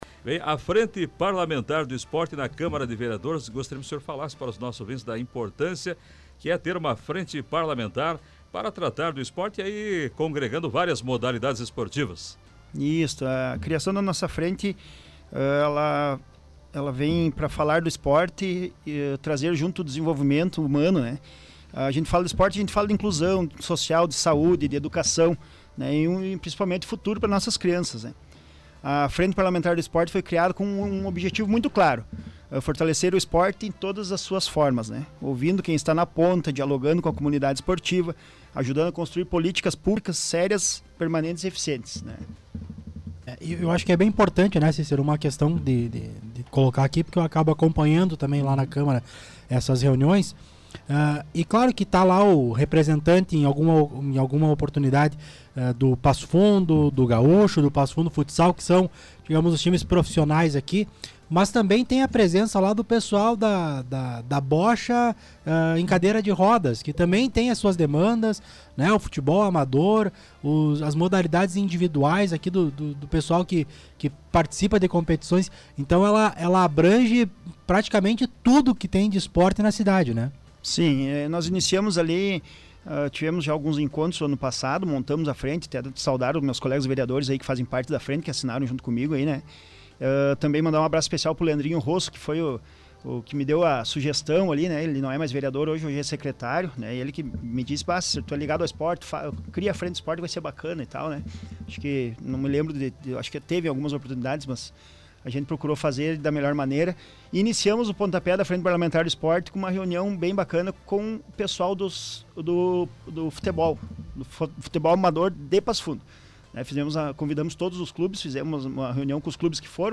O vereador Cícero Martins (PSD) participou do programa Show de Bola da Rádio Planalto News (92.1). Como presidente da Frente Parlamentar do Esporte, apresentou os trabalhos realizados até o momento e os encaminhamentos.